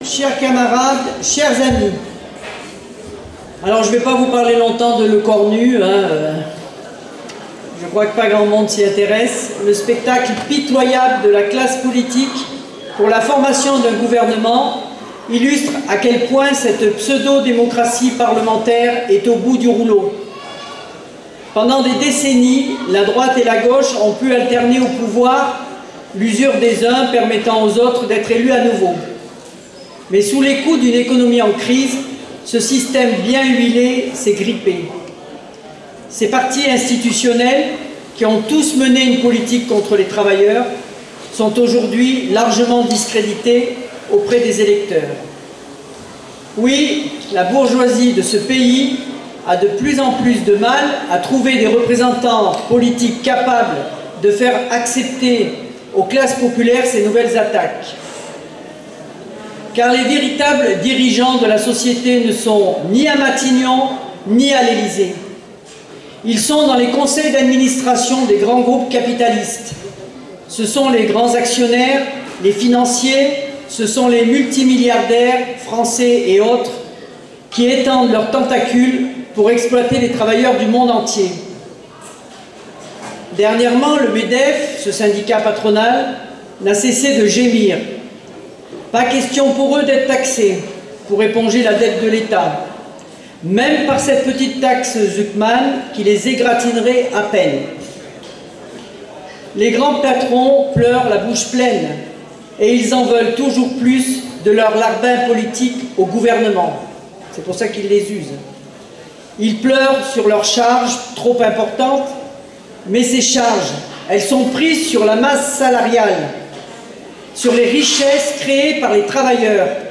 Fête de Grenoble 2025